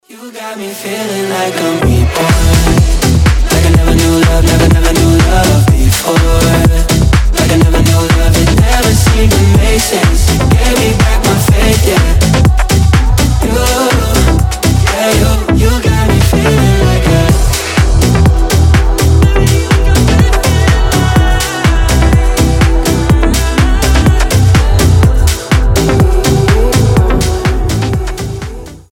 EDM
house